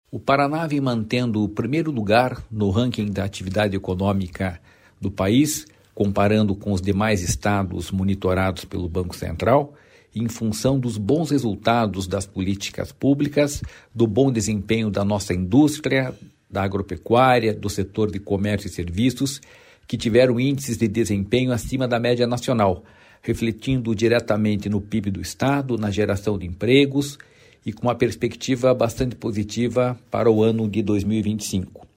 Sonora do diretor-presidente do Ipardes, Jorge Callado, sobre a liderança do Paraná na atividade econômica de janeiro a abril